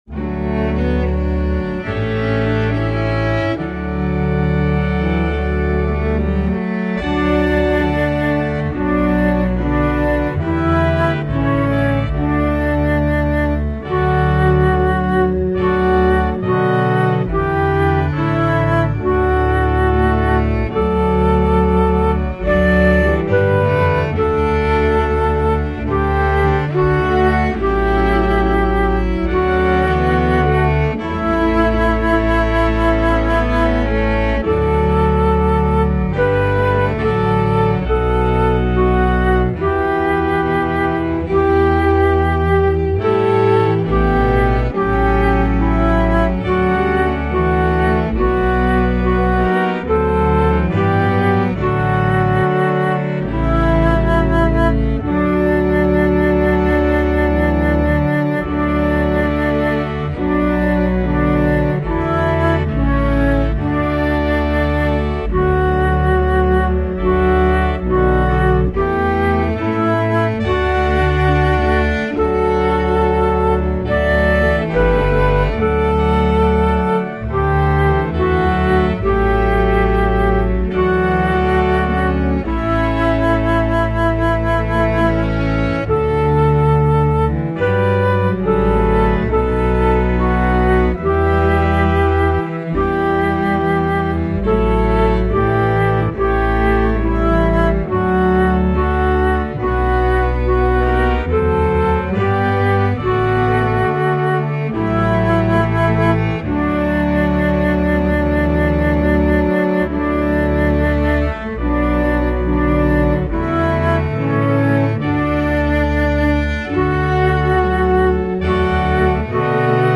My backing is awfully slow and stringy: